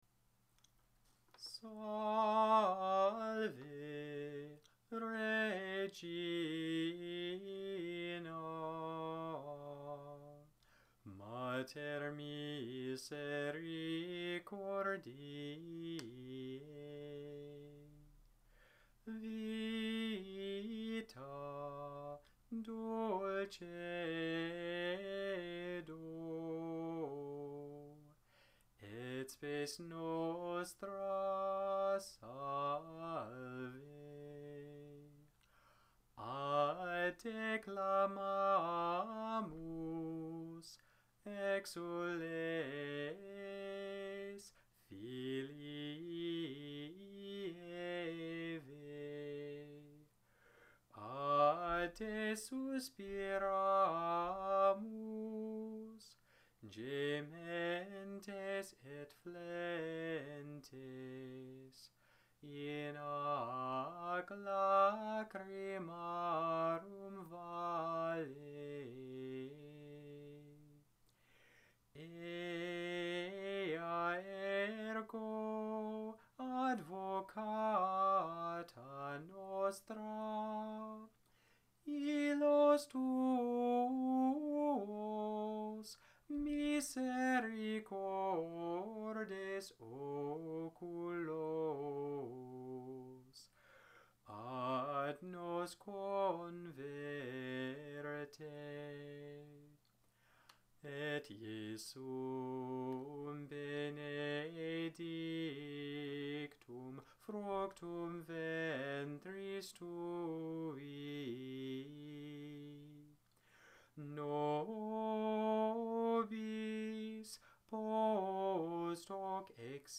Gregorian, Catholic Chant Salve Regina (solemn)
Gregorian chant audios
Salve_Regina_solemn.mp3